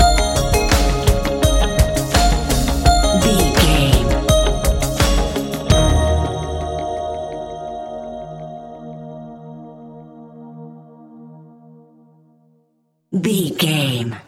Ionian/Major
D♯
electronic
techno
trance
synthesizer
synthwave